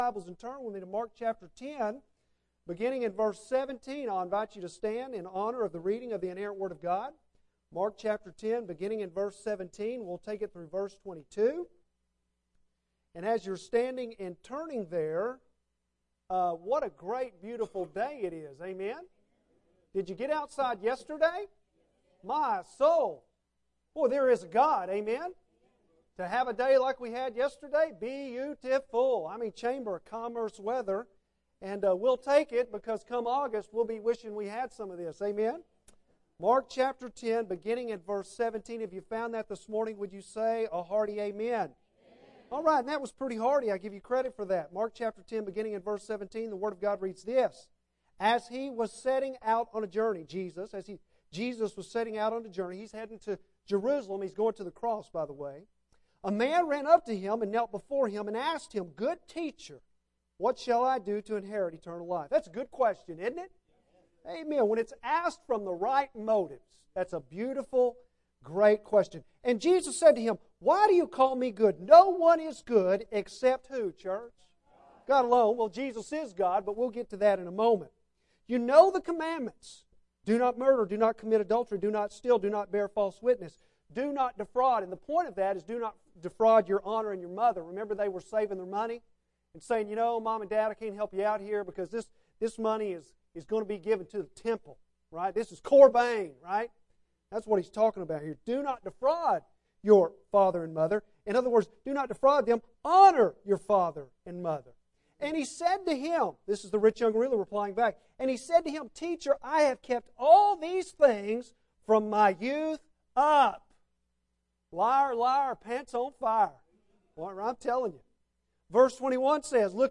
Bible Text: Mark 10:17-22 | Preacher